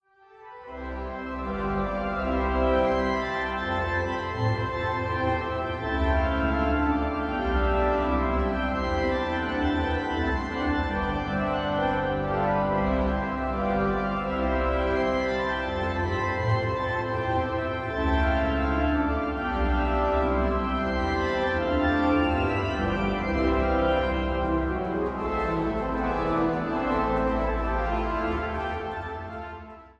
Ladegast-Orgel im Dom zu Schwerin